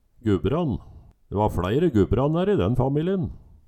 DIALEKTORD PÅ NORMERT NORSK Gubbrann Guldbrand